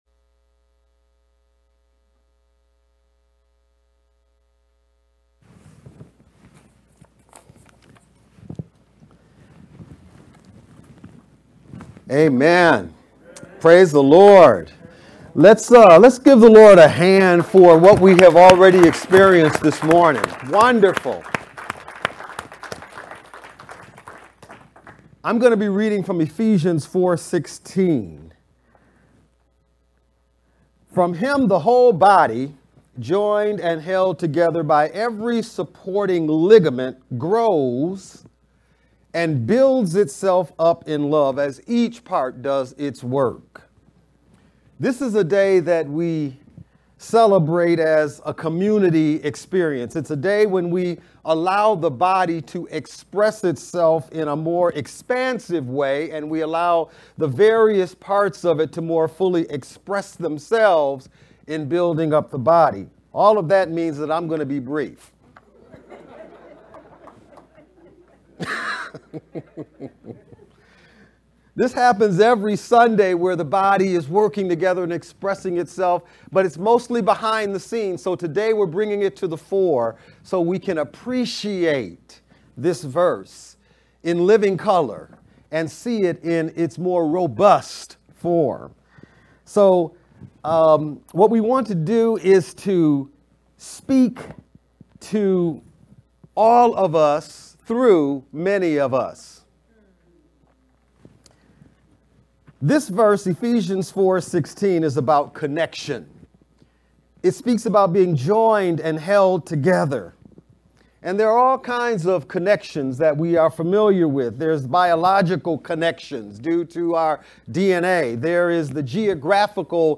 Feb-27-VBCC-Sermon-edited-MP3.mp3